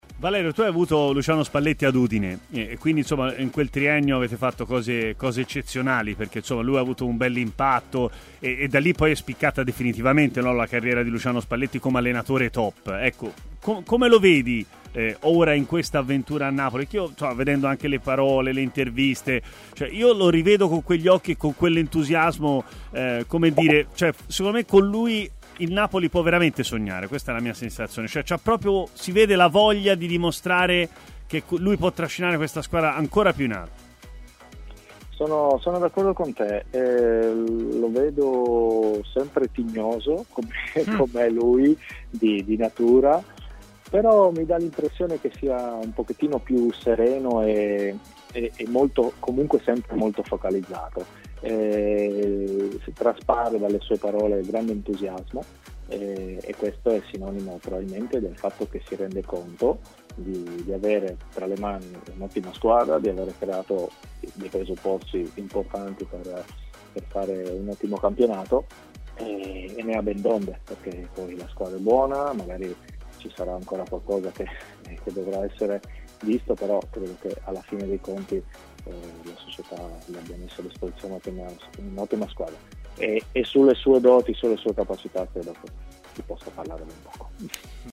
L'ex difensore dell'Udinese Valerio Bertotto, oggi allenatore, ai microfoni di Tmw Radio ha espresso il suo parere su Spalletti al Napoli: "Sempre tignoso, com'è lui di natura, ma mi pare un po' più sereno.